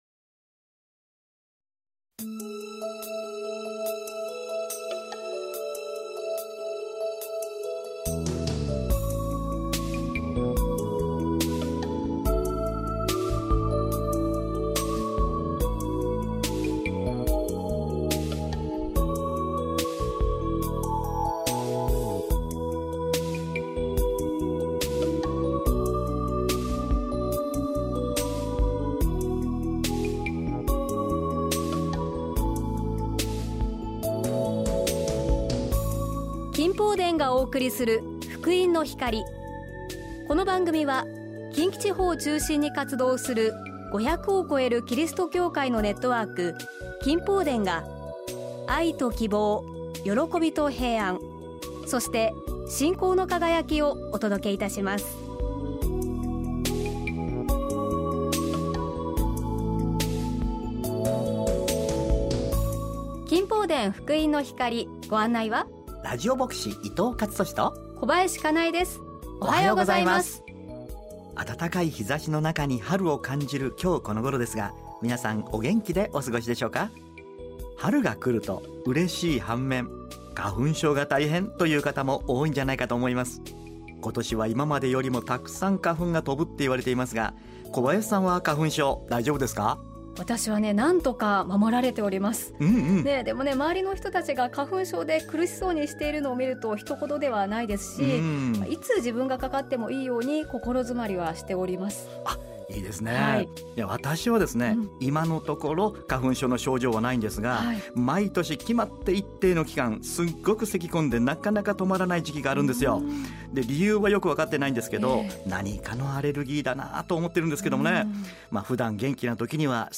御言葉とお話「神の言葉で生きる」